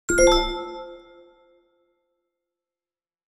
achievement.wav